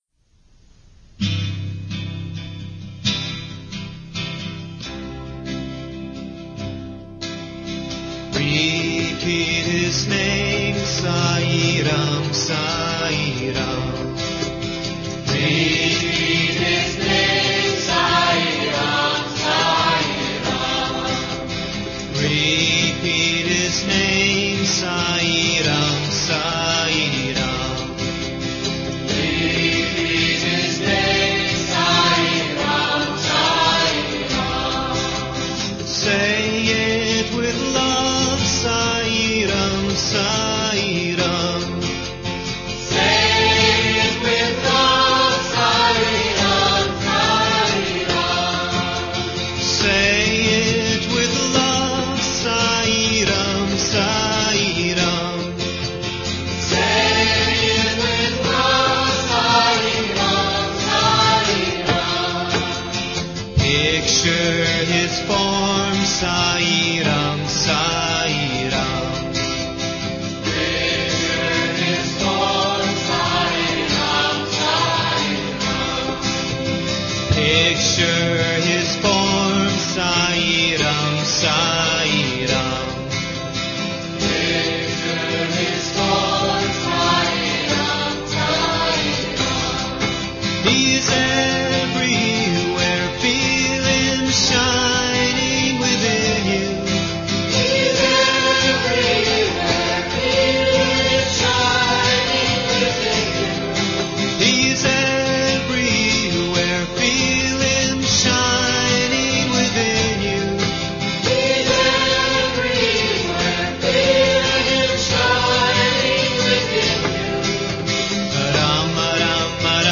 1. Devotional Songs
~ Major (Shankarabharanam / Bilawal)
8 Beat / Keherwa / Adi
Medium Fast
4 Pancham / F